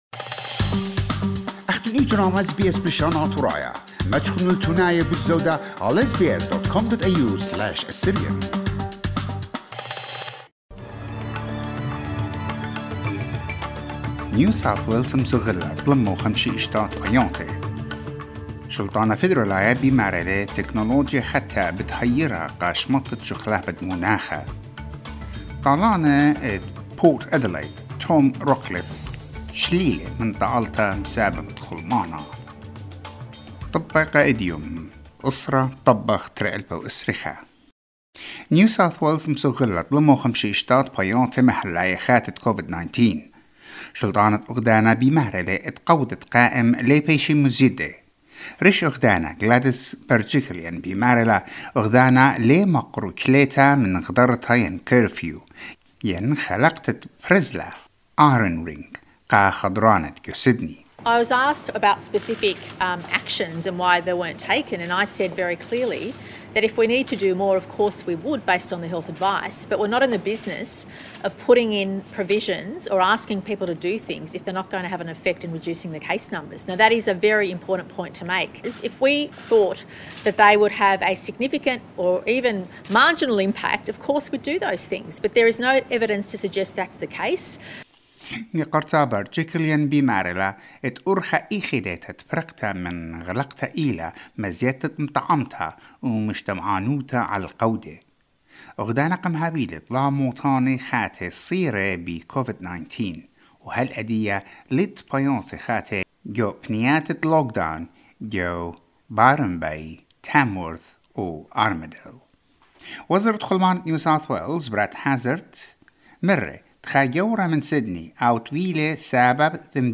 SBS NEWS IN ASSYRIAN 10 AUGUST 2021